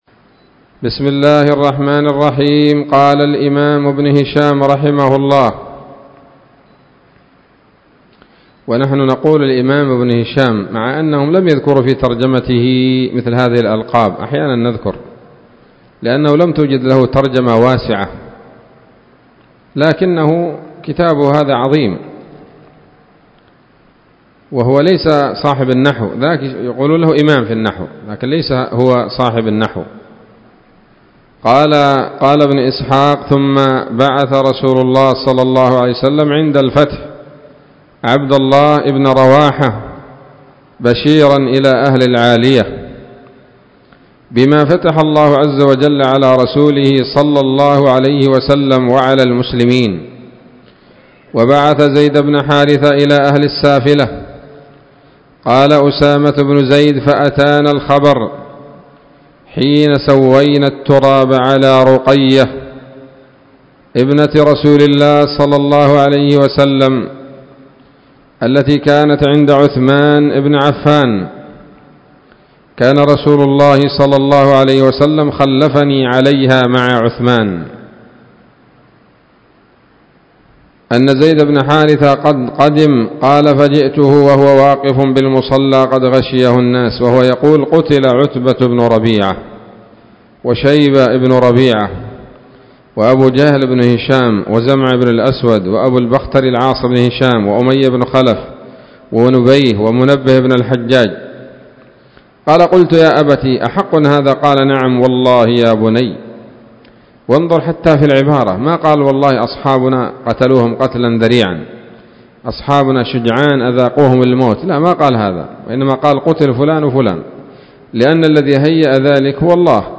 الدرس الثاني والعشرون بعد المائة من التعليق على كتاب السيرة النبوية لابن هشام